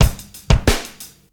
Jamaica 2 90bpm.wav